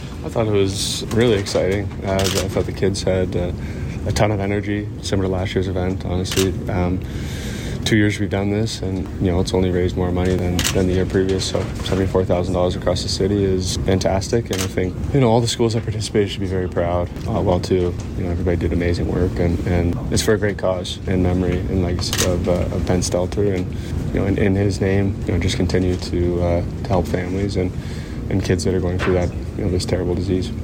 Edmonton Oiler Captain and Ben Stelter Foundation Ambassador Connor McDavid spoke with CFWE, saying he was excited to be at Ottewell School, adding that with the second year of the school fundraiser, all the kids that participated should be proud.